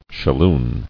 [shal·loon]